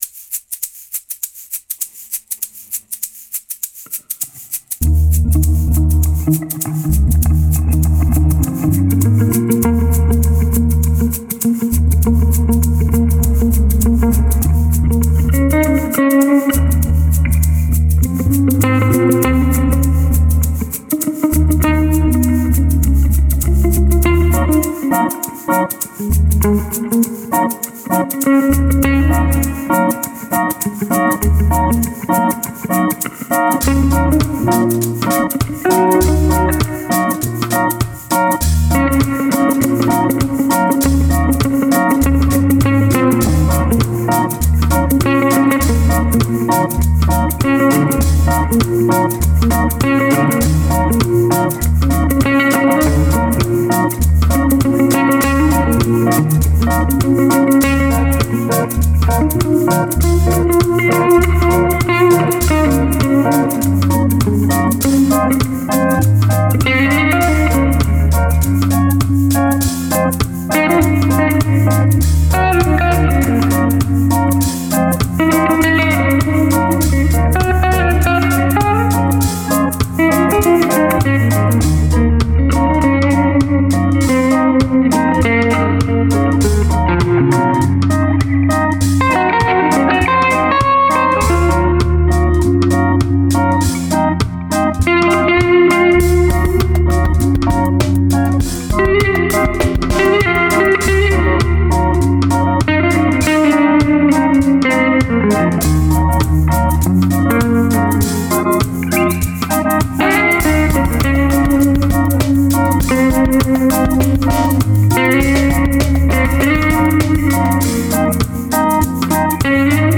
un reagge hypnotique téléchargez mp3